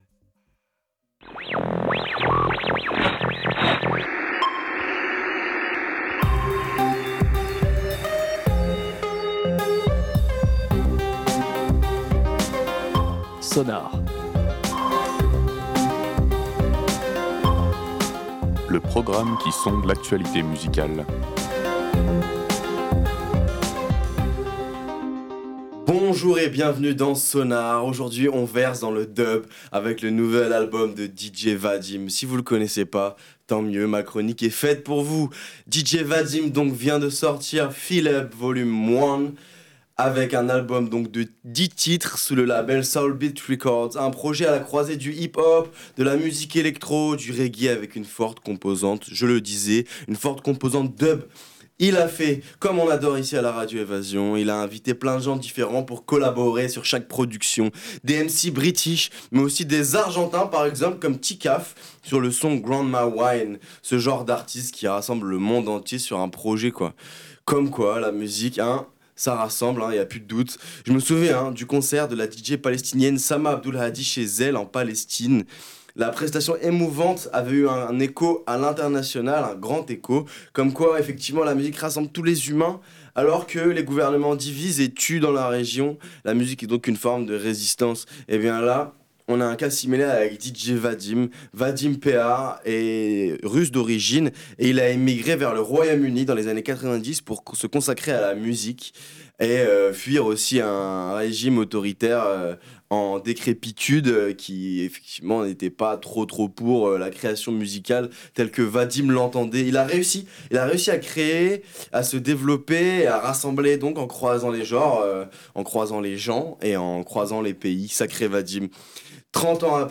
Réécoutez l'émission
Un projet à la croisée du hip hop, de la musique électro, et du reggae, avec une forte composante dub.